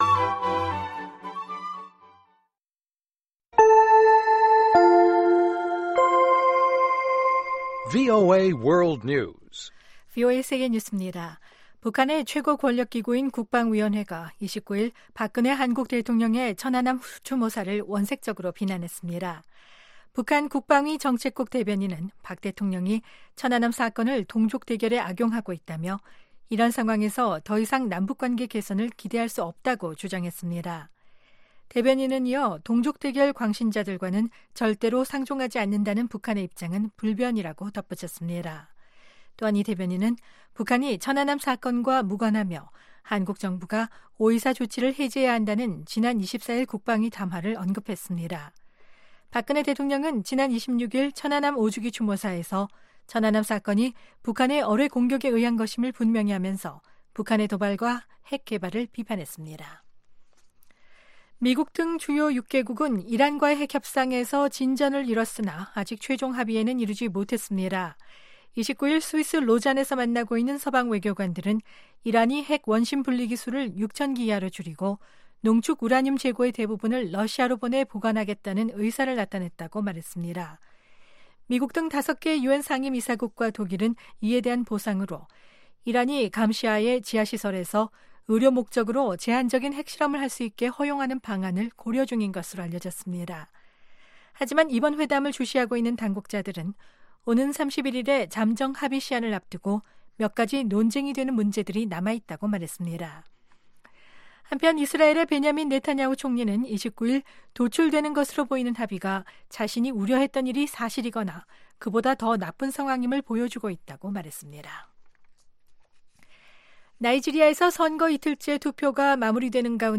VOA 한국어 방송의 월요일 오전 프로그램 2부입니다.